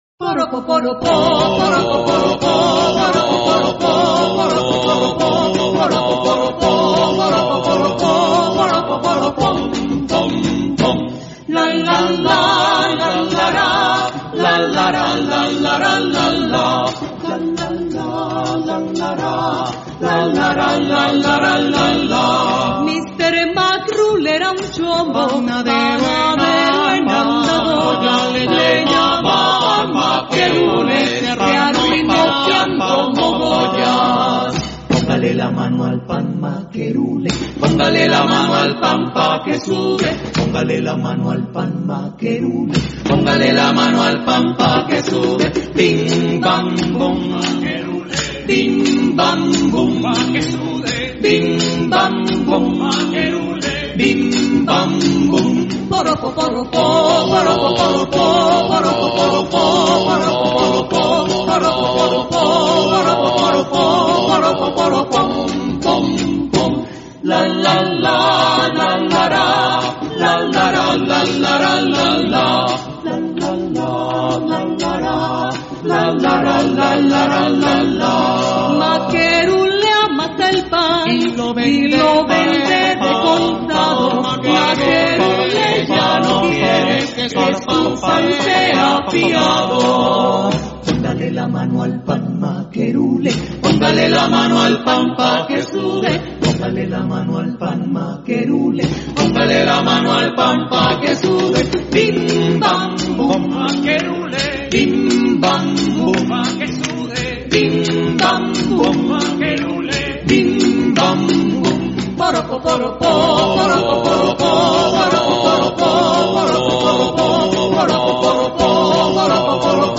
Del folclor Litoral Pacífico Colombiano